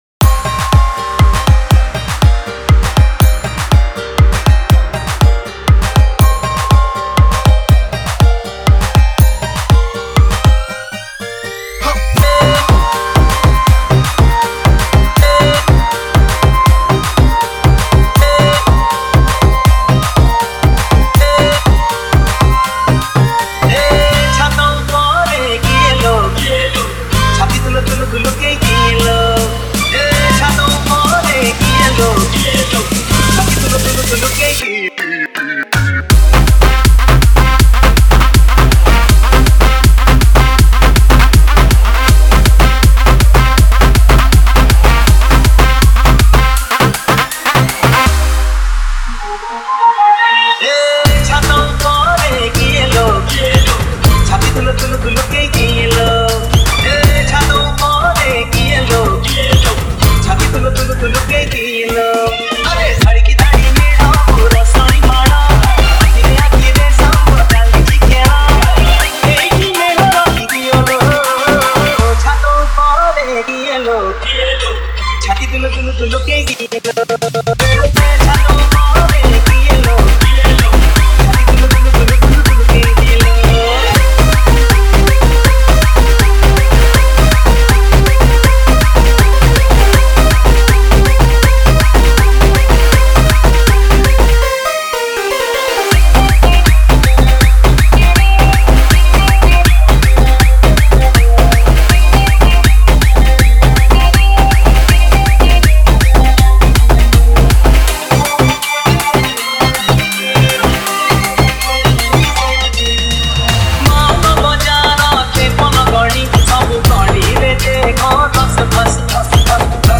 Trance Edm Mix